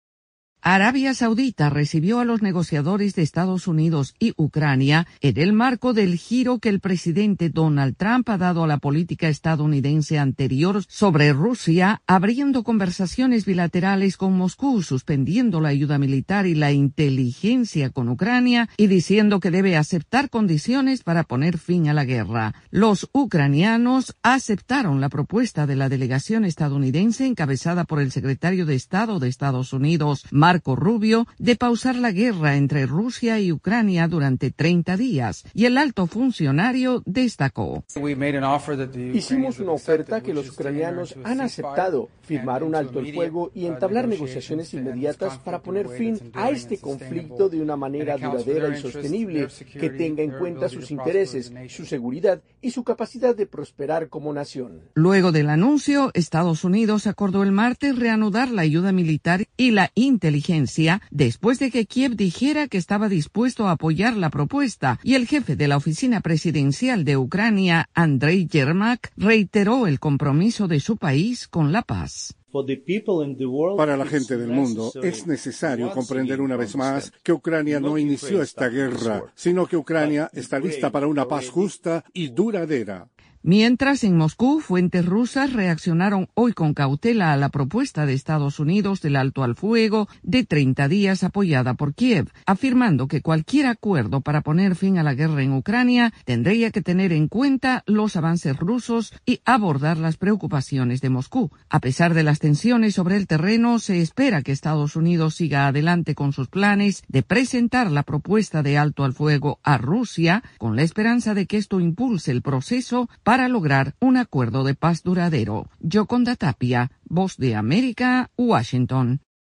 desde la Voz de América en Washington DC.